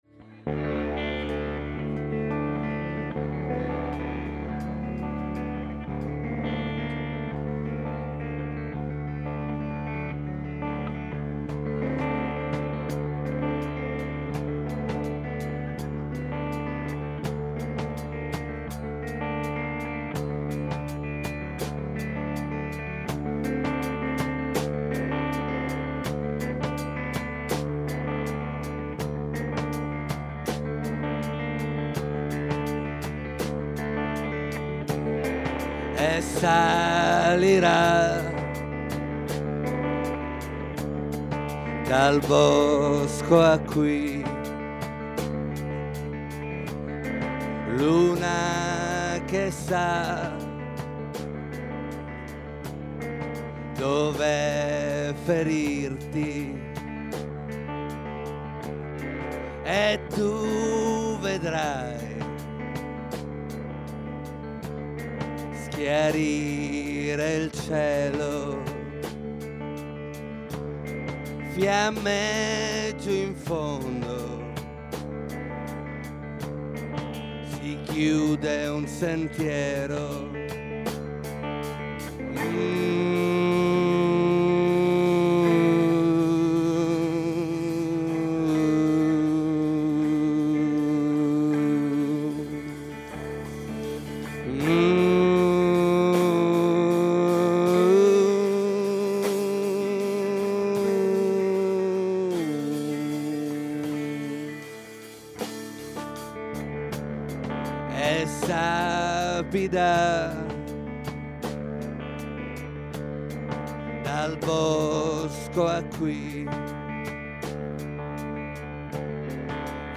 Live du groupe palermitain